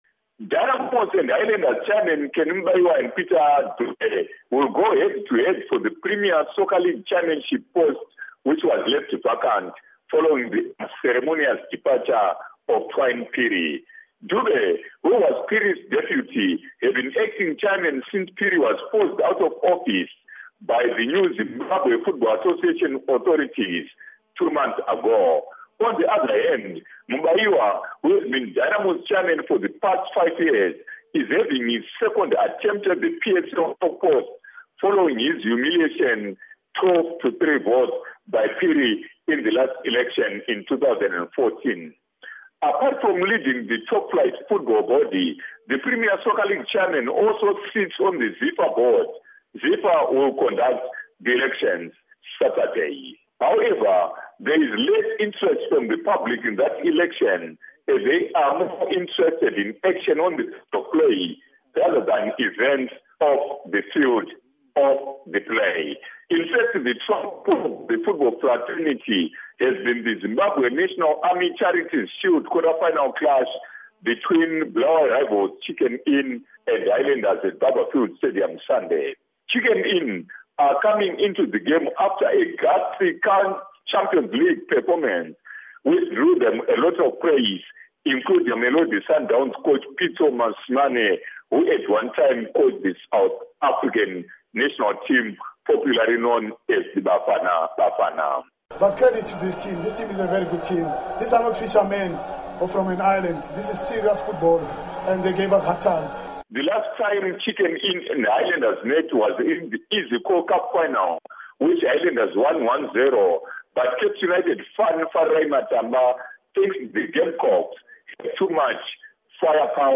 Report on PSL Chairperson, ZNA Charity Tourney